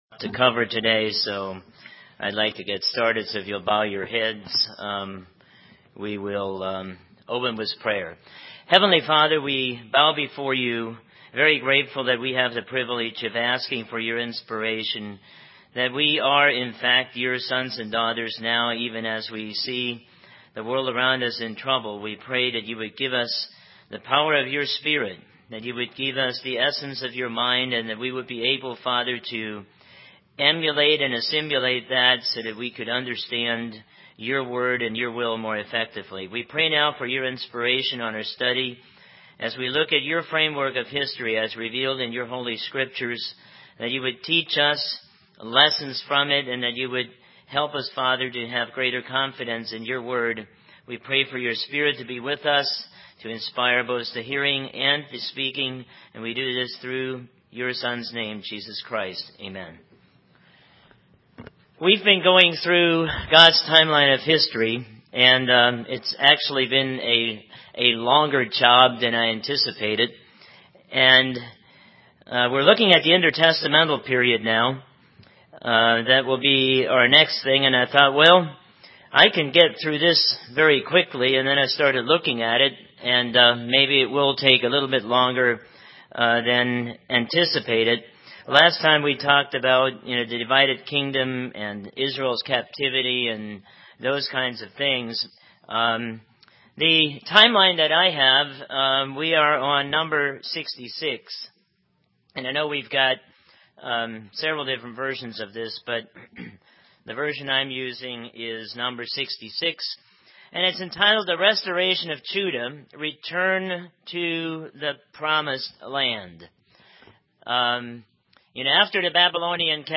Print Part eleven of God's Timeline of History: Restoration of Judah and building of the 2nd Temple UCG Sermon Studying the bible?